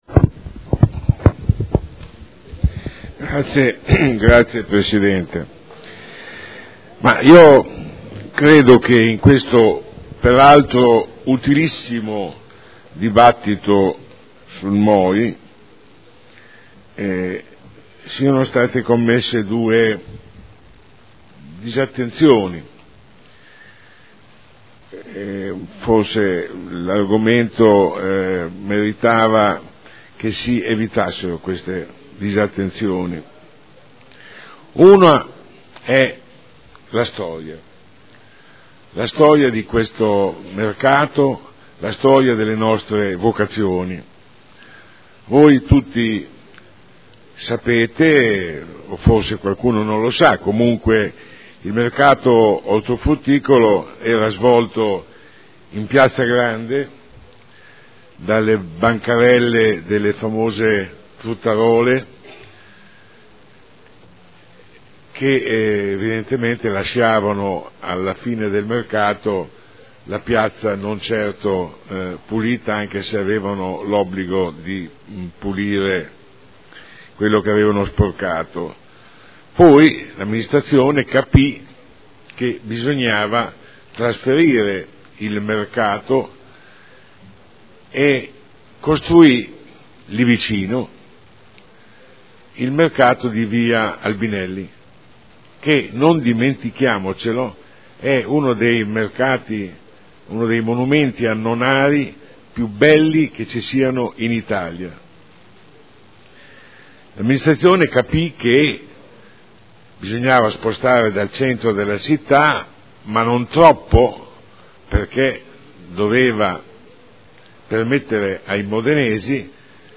Sandro Bellei — Sito Audio Consiglio Comunale
Seduta del 18/06/2012. Dibattito su proposta di deliberazione e ordine del giorno.